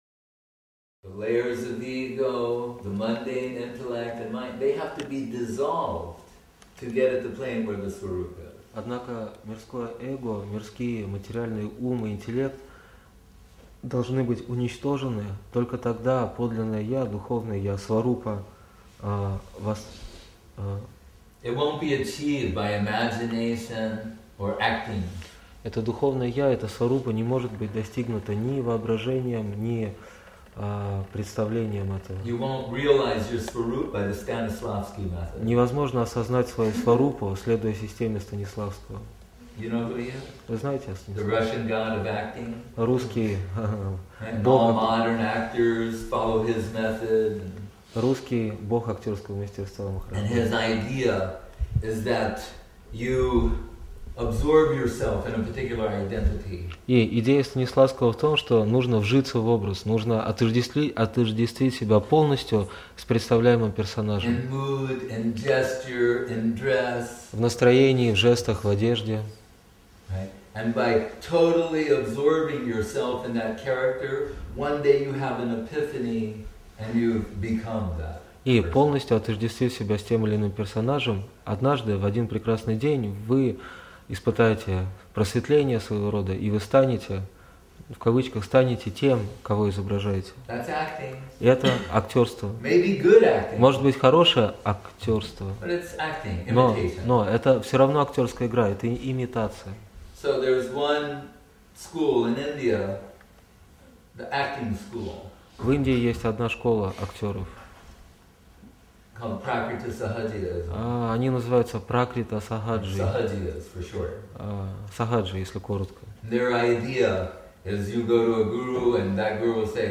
Place: Centre «Sri Chaitanya Saraswati» Moscow